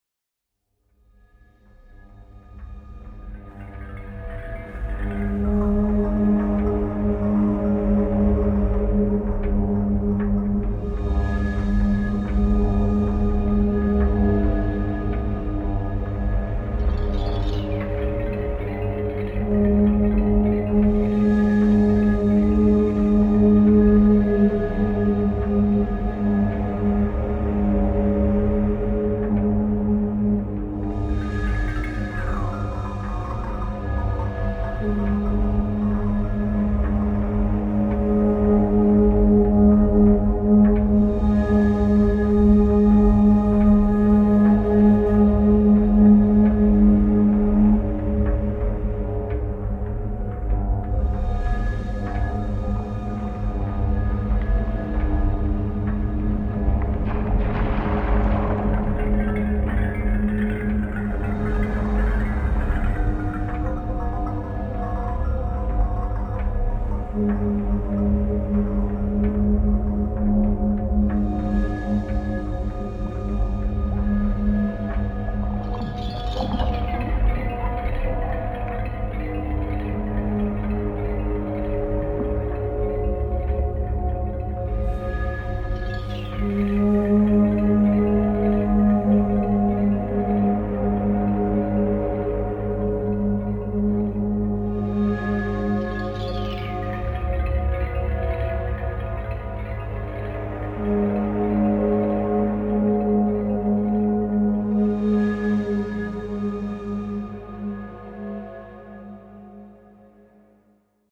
Вторая часть трилогии эмбиентной музыки
голос, флейта, полевые записи